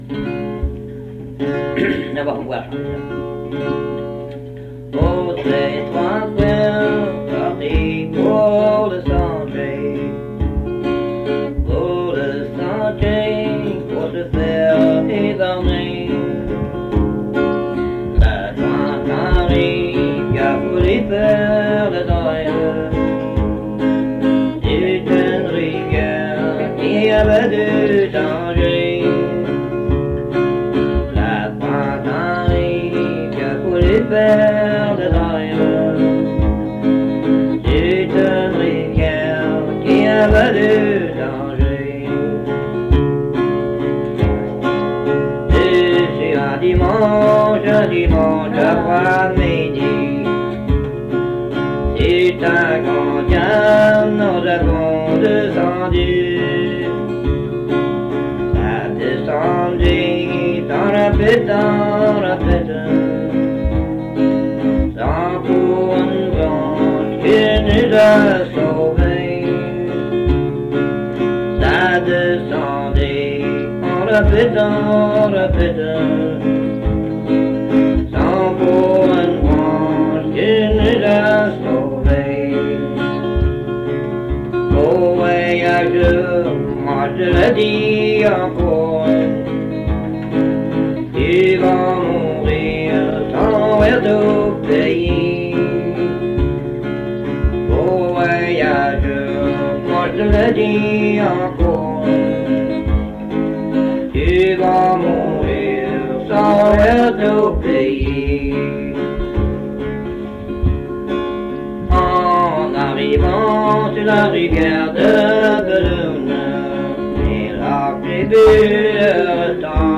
Chanson Item Type Metadata
Avec guitare